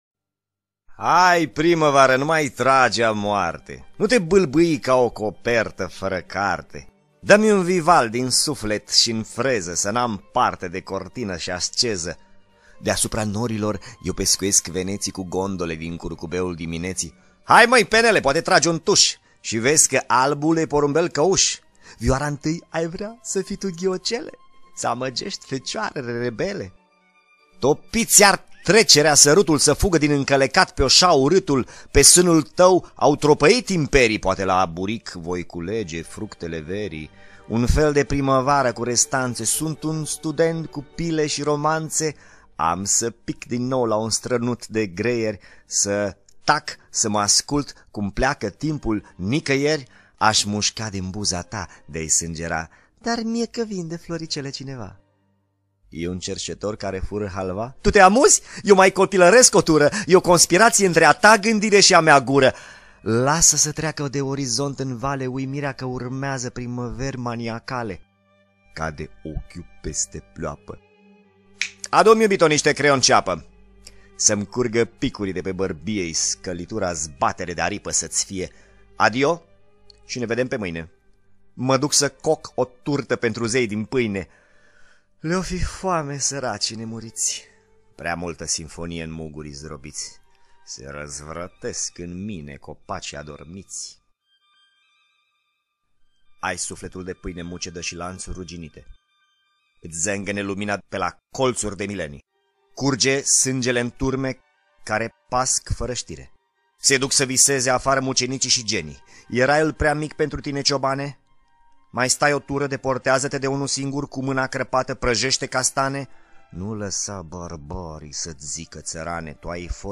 Poezii romantice
Poezii-romantice.mp3